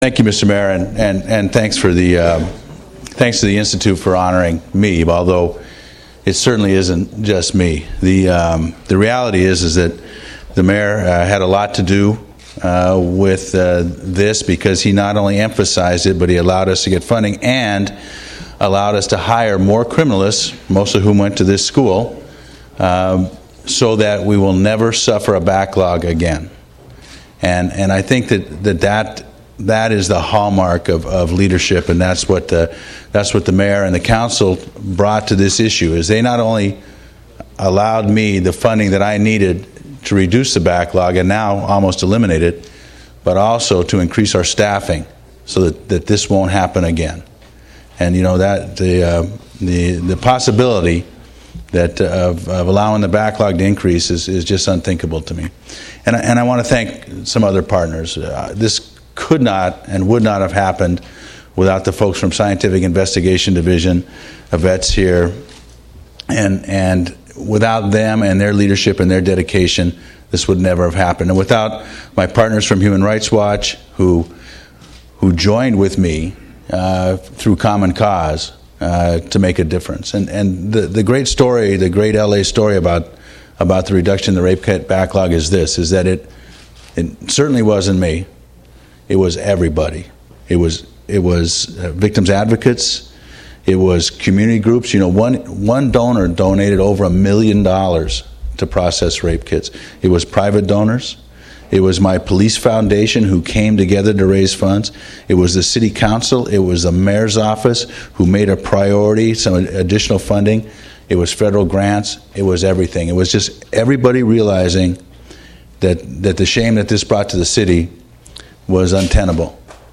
DecemberBeckimg 10, 2010 – In a small ceremony held at the Hertzberg-Davis Forensic Science in California State University, Los Angeles campus, Police Chief Charlie Beck was honored by the California Forensic Science Institute for his contributions in influencing Scientific Investigation Division operations and addressing their resource needs.